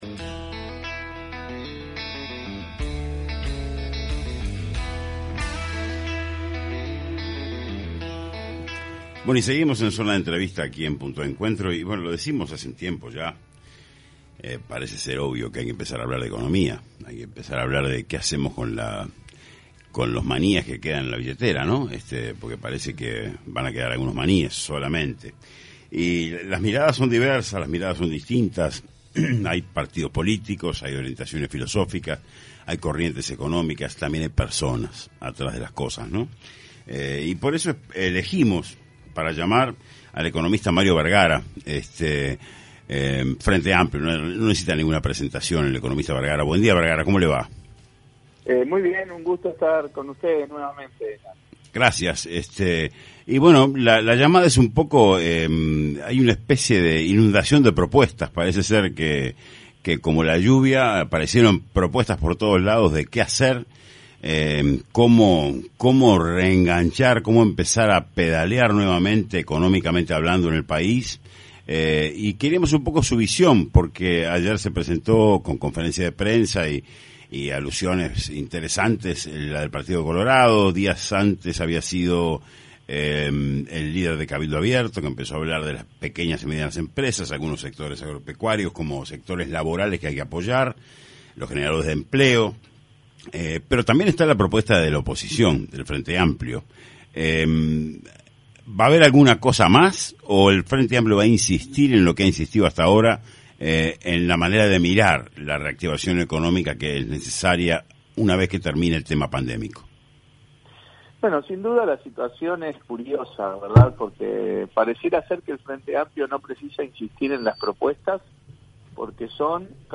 En entrevista en Punto de Encuentro el senador del Frente Amplio Mario Bergara hizo referencia a los proyectos presentados por los partidos de la coalición de gobierno, en concreto a los presentados por el Partido Colorado.